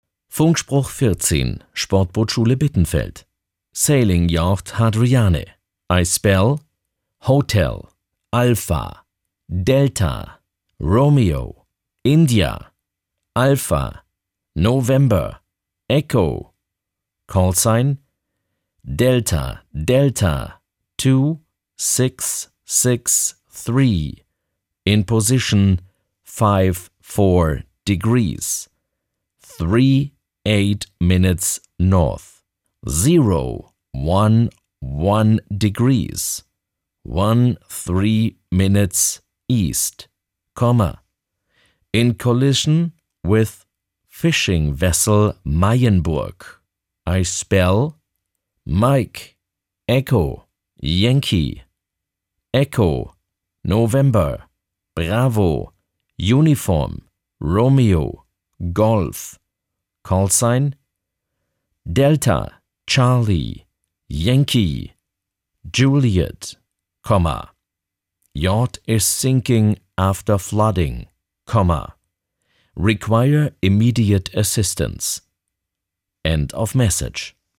Funkspruch 14 – Sportbootschule Bittenfeld
Funkspruch-14.mp3